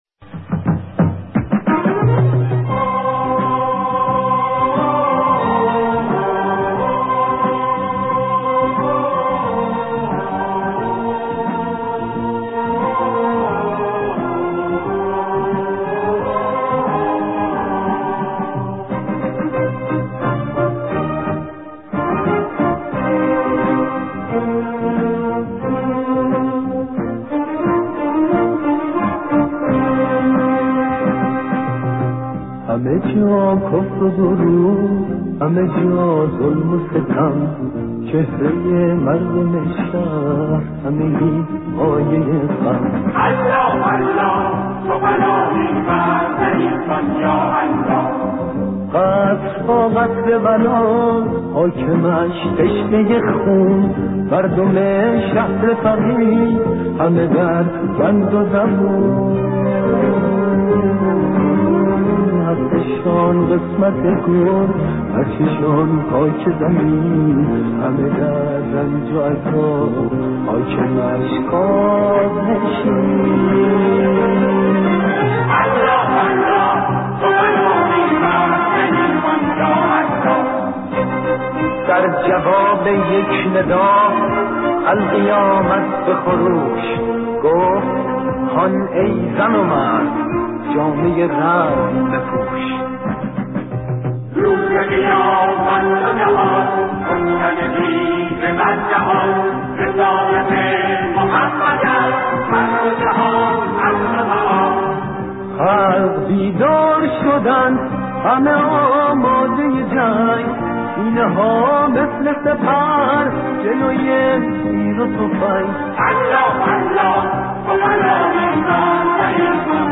سرود انقلاب؛ الله الله تو پناهی بر ضعیفان یا الله